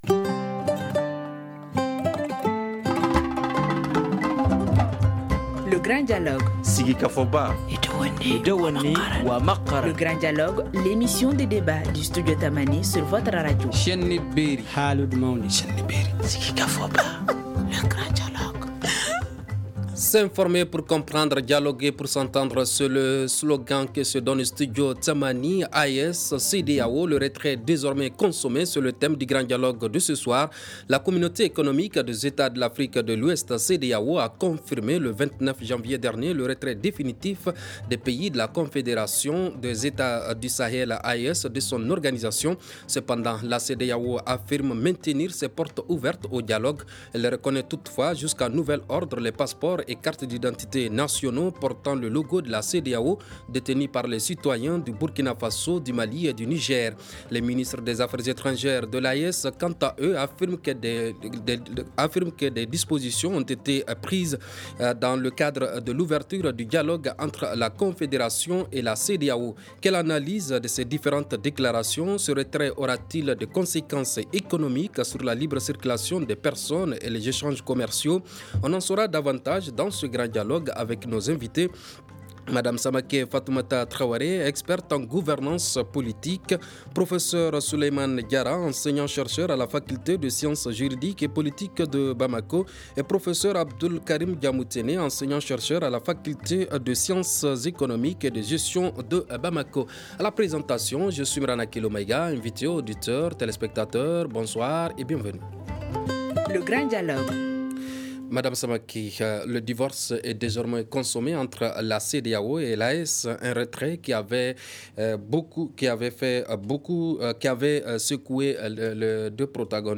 On en saura davantage dans ce grand dialogue avec nos invités :